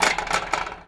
pgs/Assets/Audio/Metal/metal_rattle_spin_small_04.wav at master
metal_rattle_spin_small_04.wav